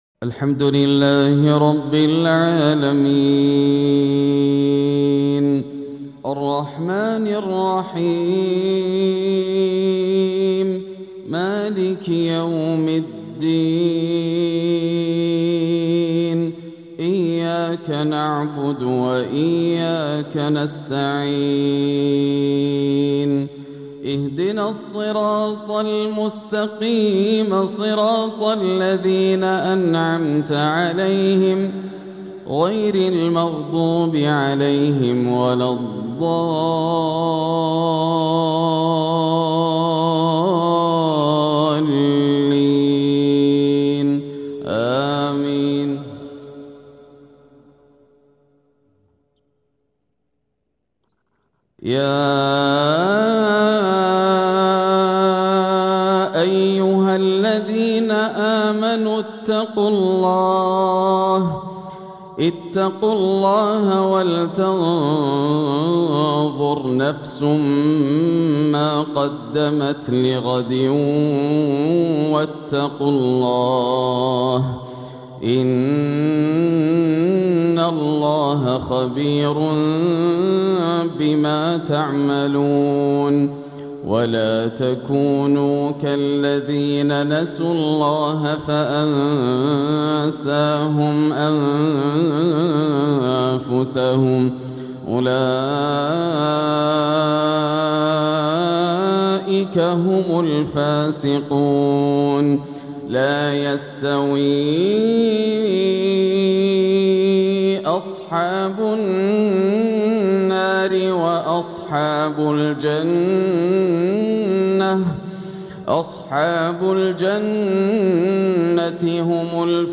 تلاوة لآخر سورة الحشر | عشاء الأحد 6-4-1447هـ > عام 1447 > الفروض - تلاوات ياسر الدوسري